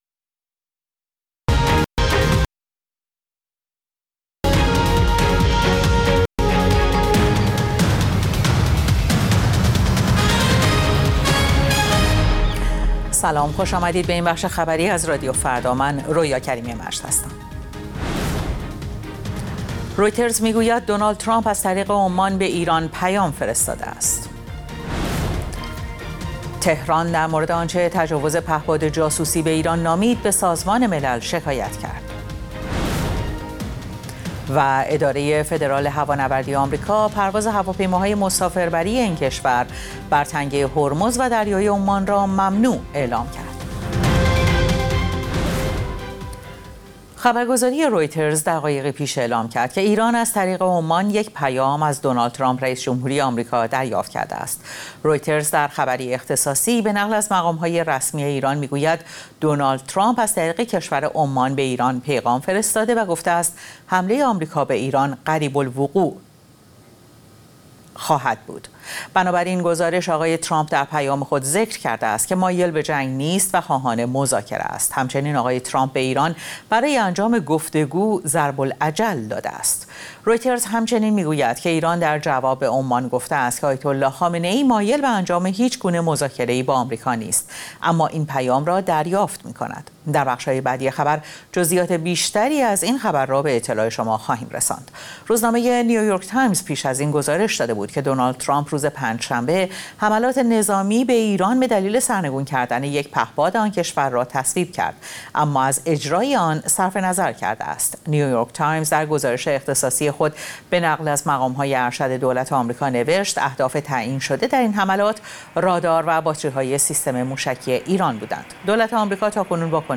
اخبار رادیو فردا، ساعت ۱۳:۰۰